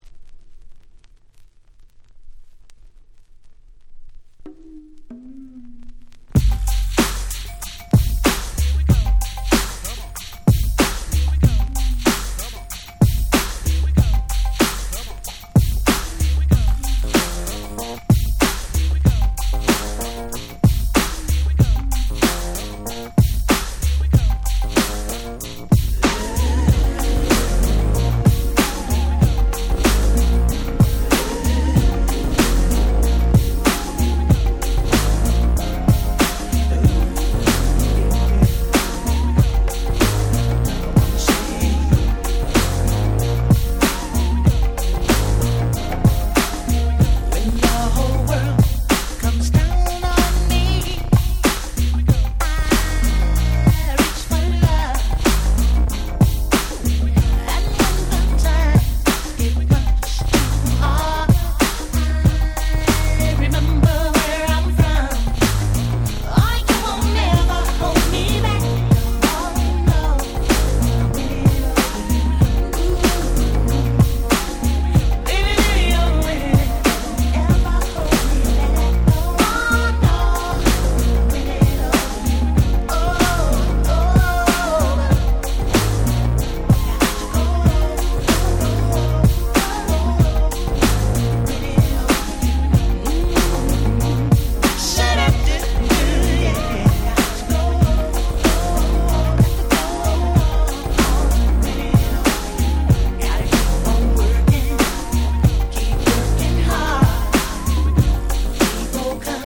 94' Nice R&B/Hip Hop Soul !!